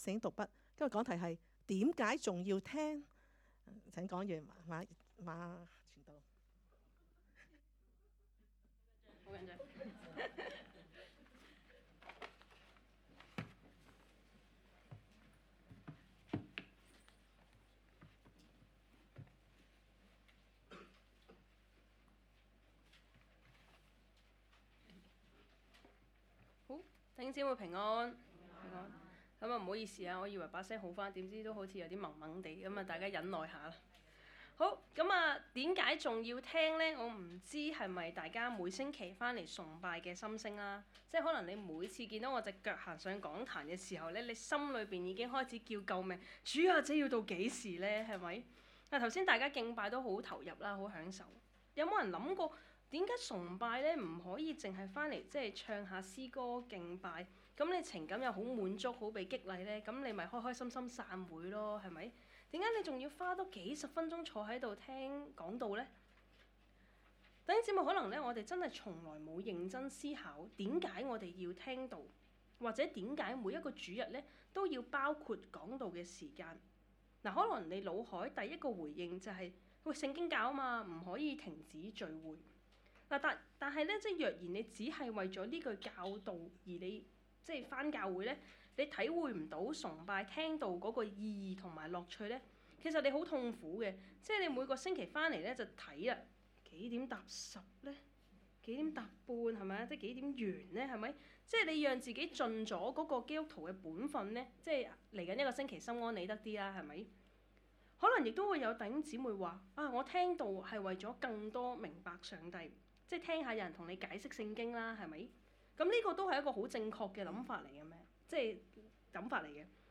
講道 ：點解仲要聽?